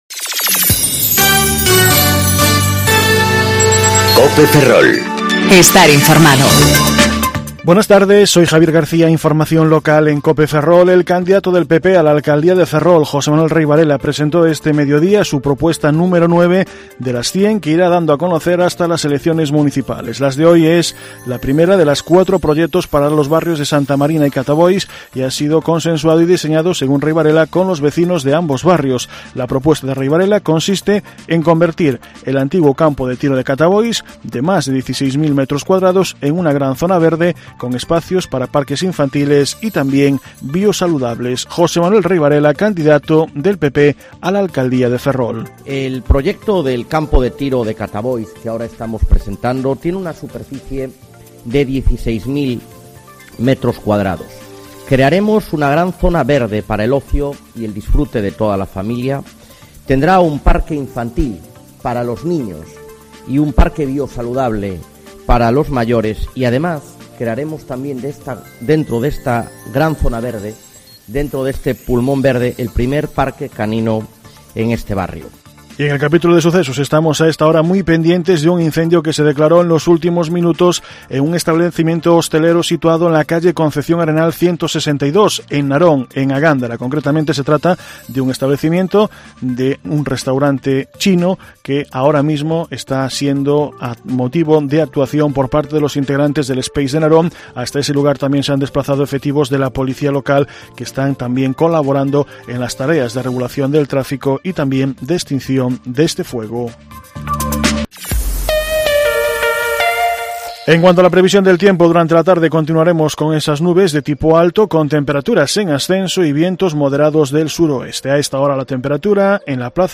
Informativo Mediodía Cope Ferrol 22/02/2019 (De 14.20 a 14.30 horas)